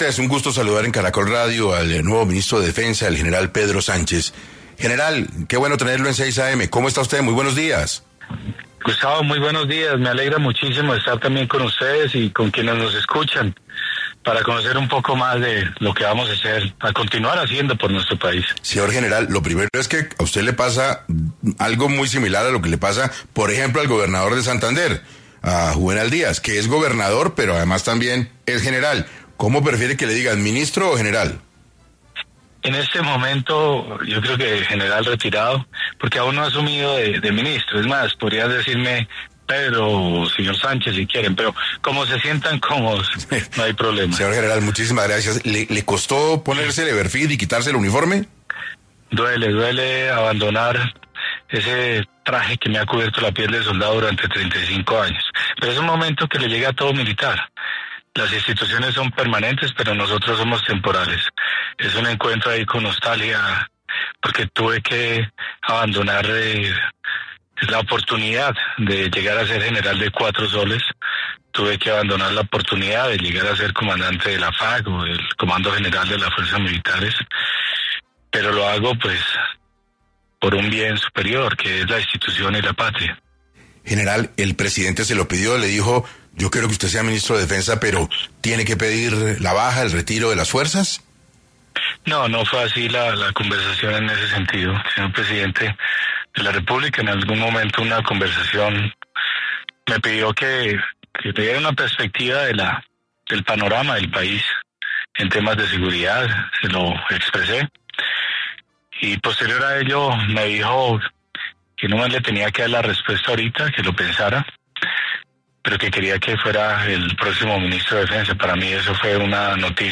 En 6AM de Caracol Radio estuvo el general en proceso de retiro, Pedro Sánchez, quien será el nuevo ministro de Defensa, para hablar sobre su experiencia para contrarrestar la situación que se vive con el orden público en el país.